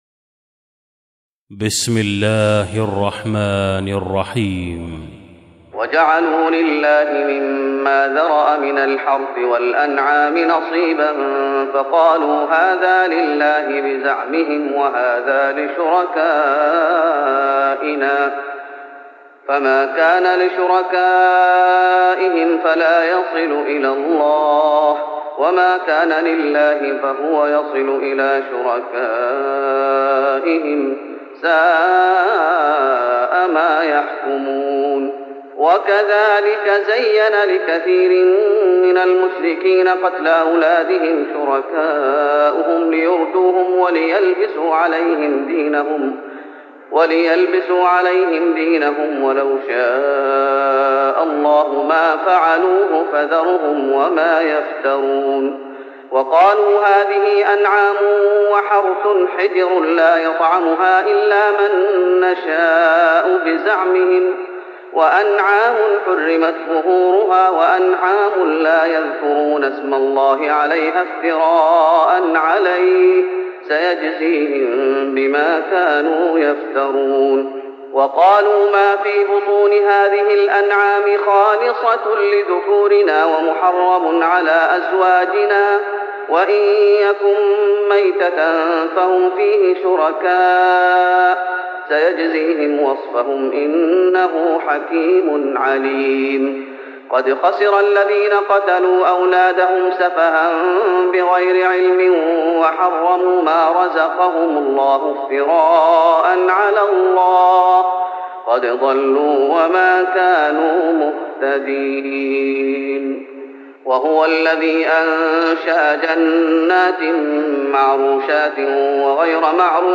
تراويح رمضان 1415هـ من سورة الأنعام (136-165) Taraweeh Ramadan 1415H from Surah Al-An’aam > تراويح الشيخ محمد أيوب بالنبوي 1415 🕌 > التراويح - تلاوات الحرمين